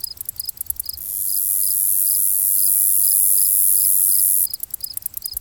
CICADA CR05L.wav